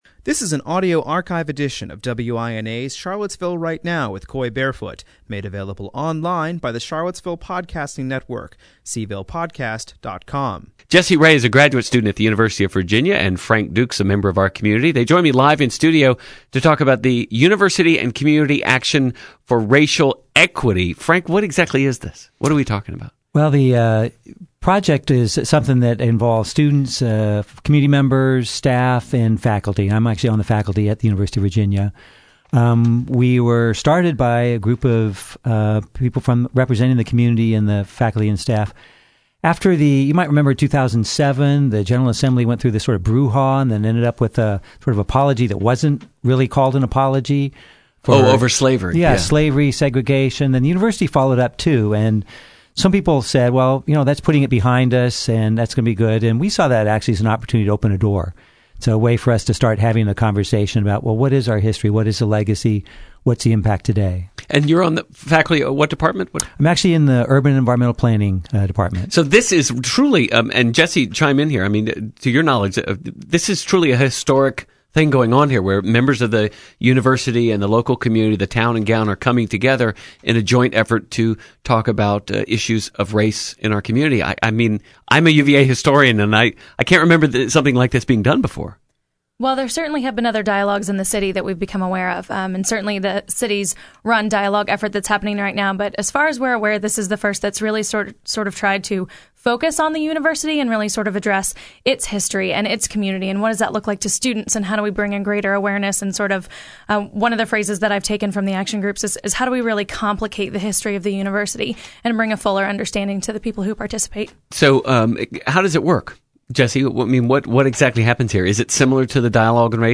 Interviews , WINA